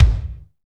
Index of /90_sSampleCDs/Northstar - Drumscapes Roland/DRM_Techno Rock/KIK_F_T Kicks x